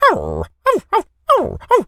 dog_whimper_cry_01.wav